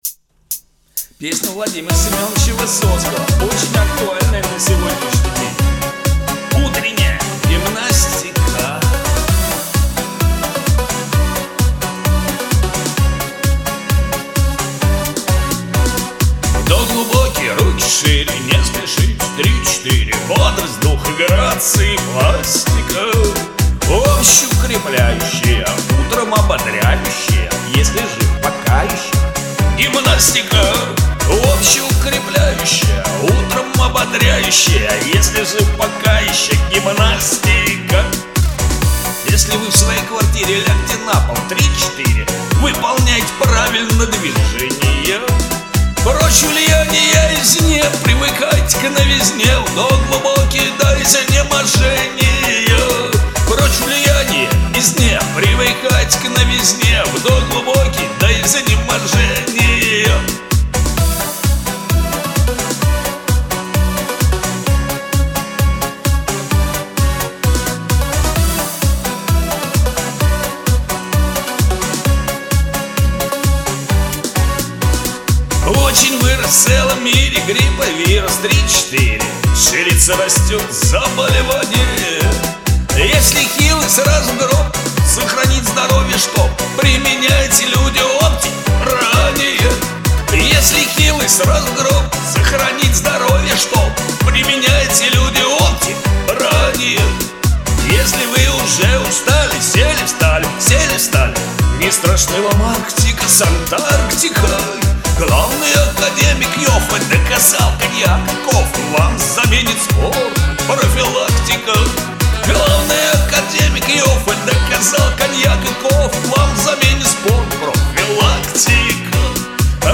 что Ваша чисто ресторанная запись